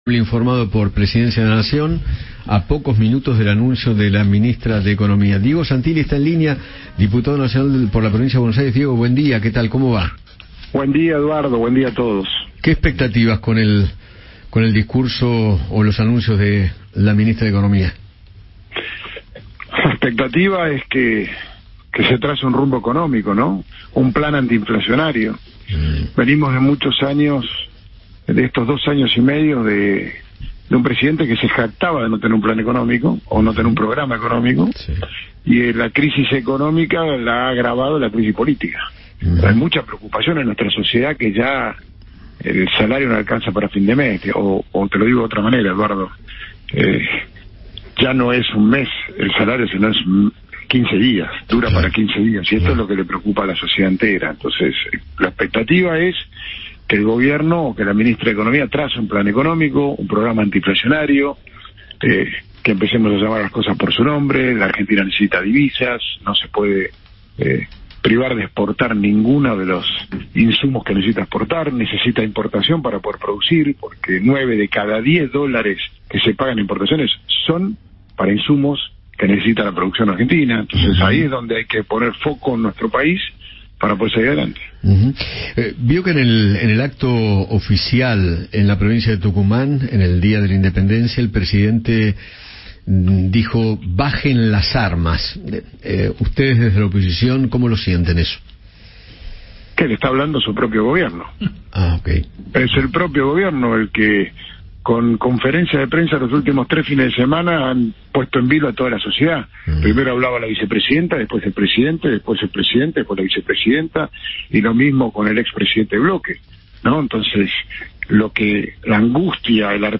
Diego Santilli, diputado de Juntos por el Cambio, conversó con Eduardo Feinmann sobre el panorama político del país y analizó el presente económico, tras las modificaciones en el ministerio.